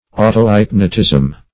Search Result for " autohypnotism" : The Collaborative International Dictionary of English v.0.48: Autohypnotism \Au`to*hyp"no*tism\, n. [Auto- + hypnotism.]